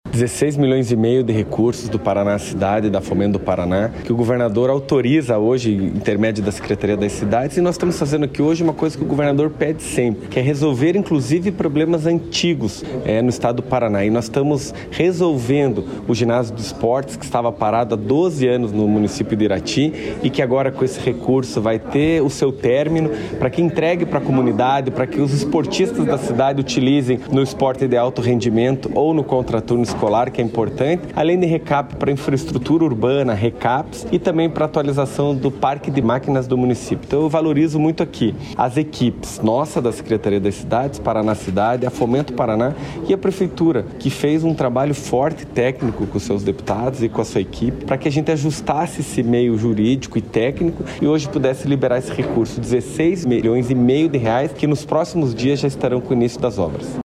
Sonora do secretário das Cidades, Eduardo Pimentel, sobre o repasse de R$ 16,5 milhões para pavimentação e conclusão de ginásio em Irati